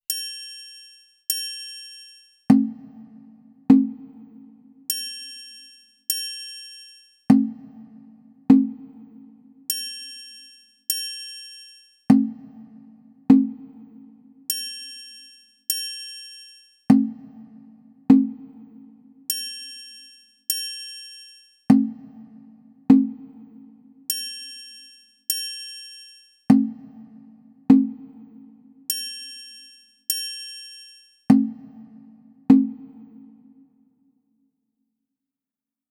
Motifs musicaux